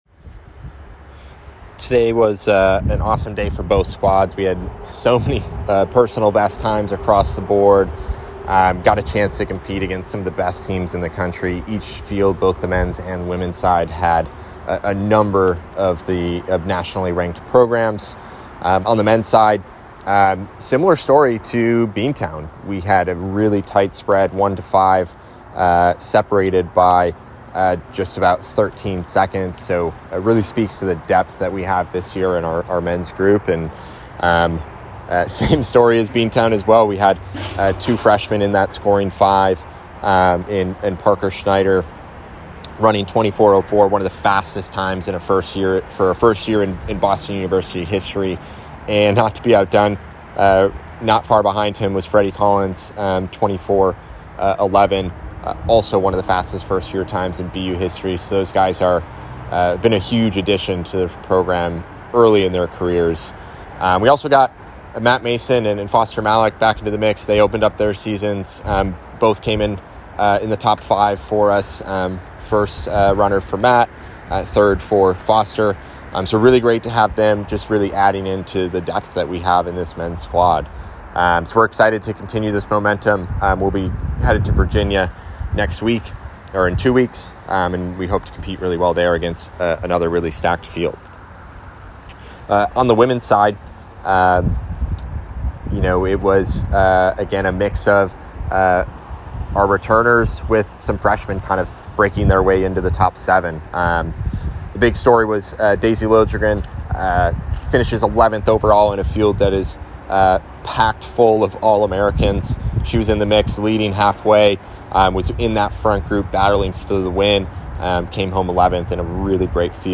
Paul Short Invite Post-race Interview